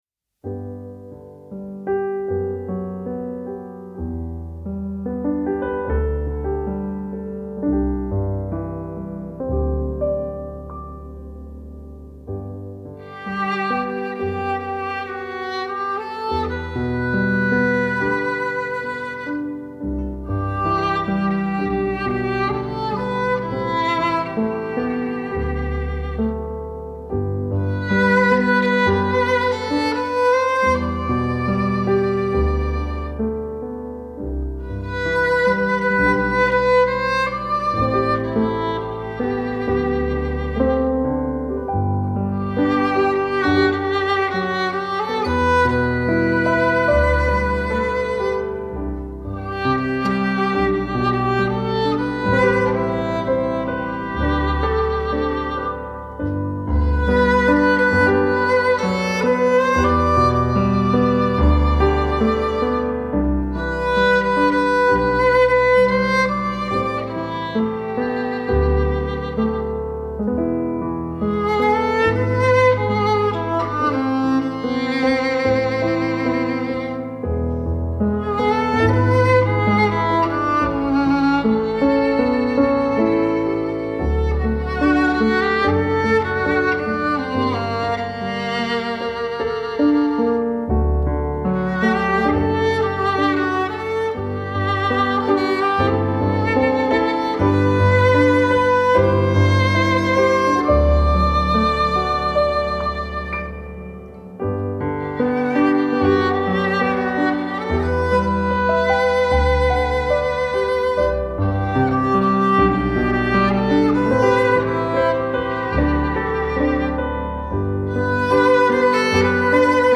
موسیقی کنار تو
پیانو الهام بخش موسیقی بی کلام نیو ایج ویولن آرامبخش